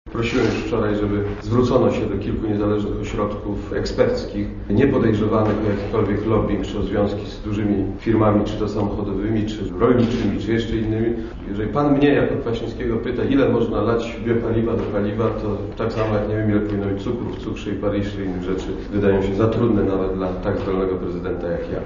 Ja też nie mam technicznej wiedzy - mówi prezydent Kwaśniewski: